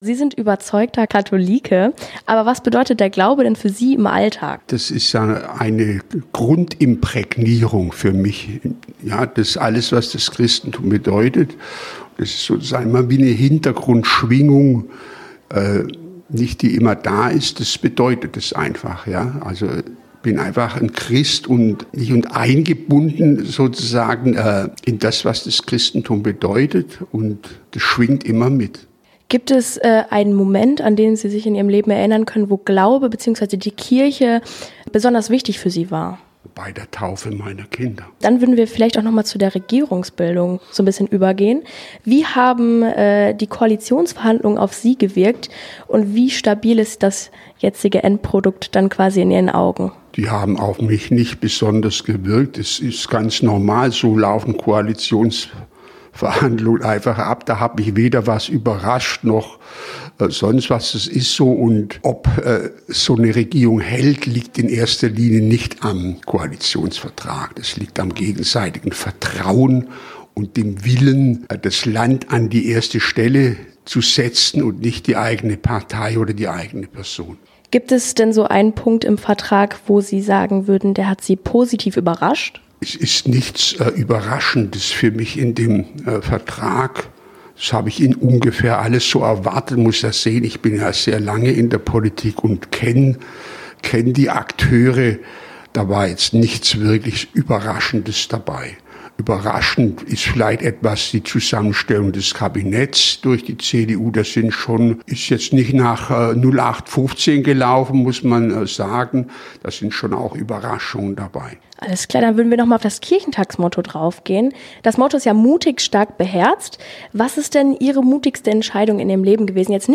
Radio Hitwave – Interview mit Winfried Kretschmann auf dem Kirchentag
Das Interview fand auf dem Kirchentag in Hannover statt. Es ging vor allem um die politische Situation und seine Verbindung zum Glauben und zum Kirchentag.
radio-hitwave-interview-mit-winfried-kretschmann-auf-dem-kirchentag